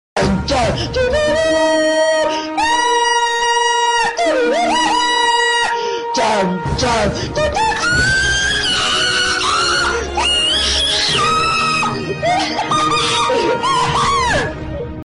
Meme Sound Effect for Soundboard